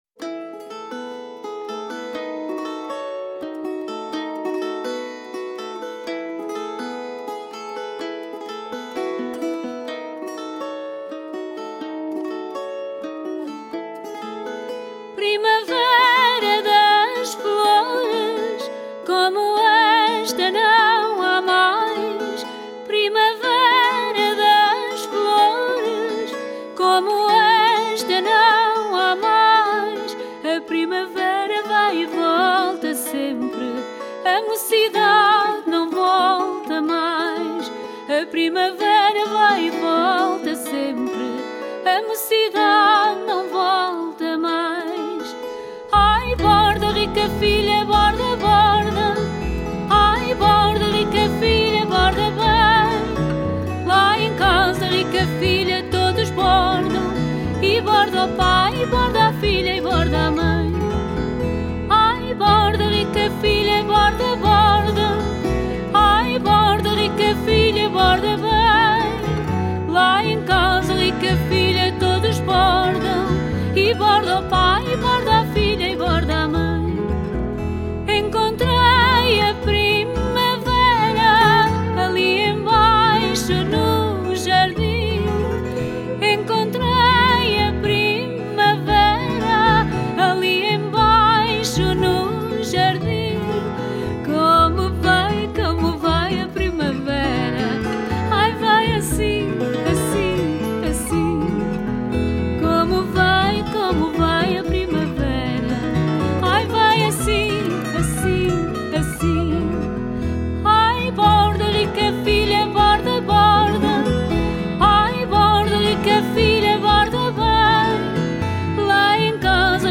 FADO